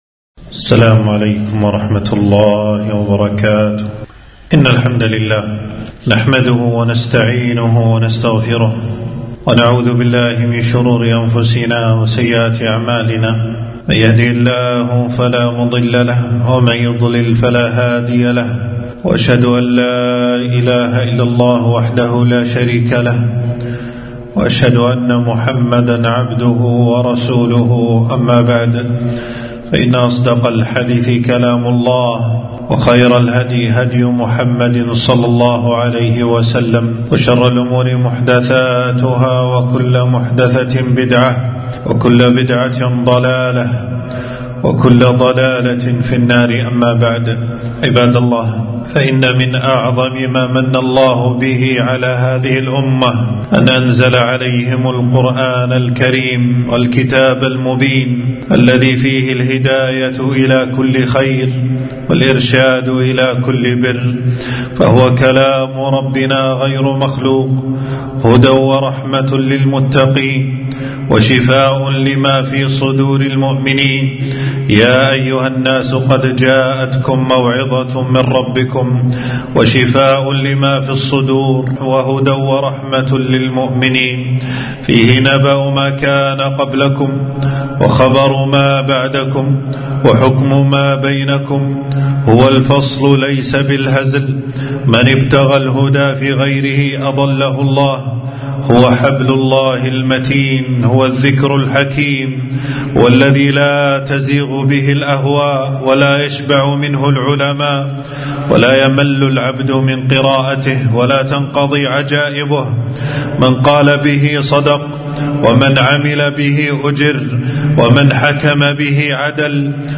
خطبة - مكانة القرآن في قلوب المسلمين